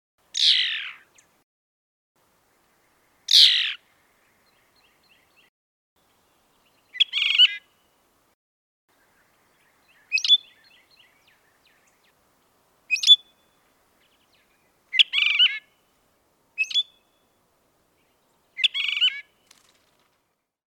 Звуки свиристелей
На этой странице собраны записи пения свиристелей – птиц с удивительно нежным и мелодичным голосом.